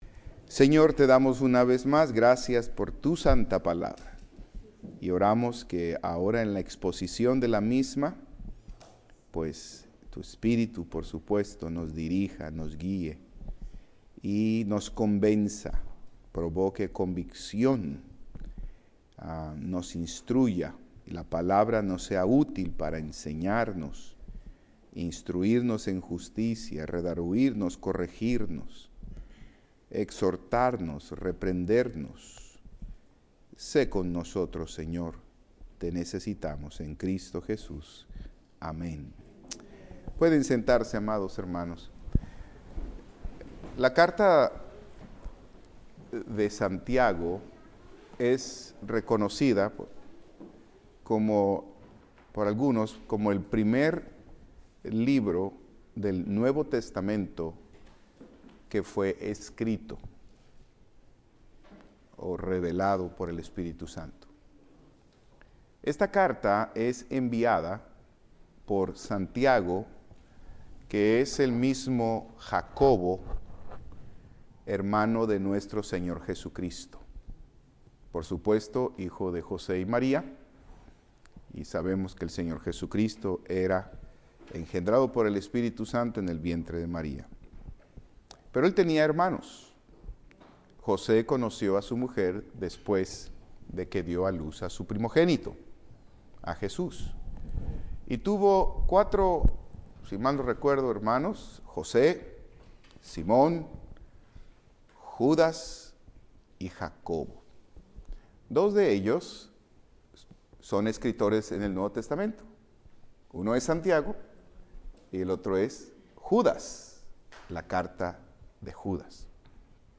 Servicio Miércoles
Sabemos que somos salvos por la fe - y también por la gracia y también por la sangre de Cristo, cada uno en un sentido diferente. Este sermón examina que significa el contexto en Santiago 2.